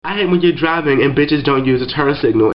Turn Signal